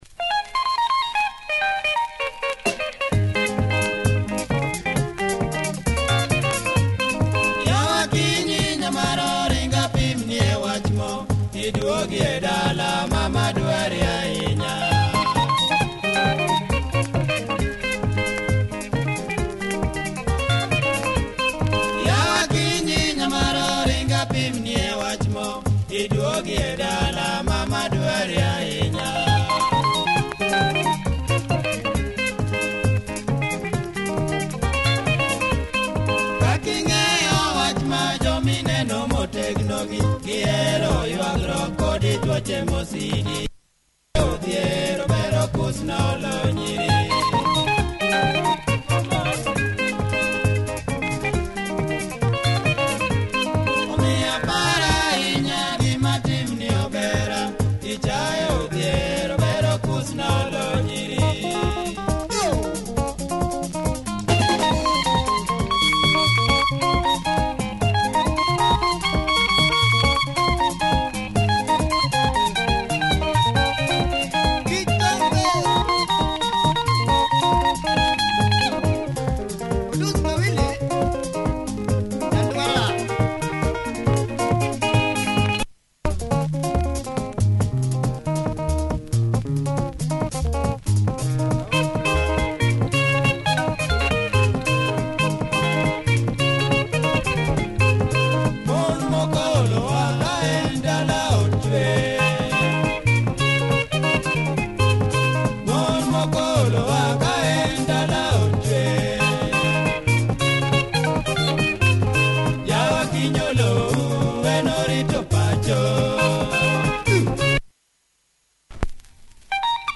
Nice luo benga by this great group